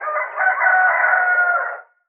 rooster_crowing_02.wav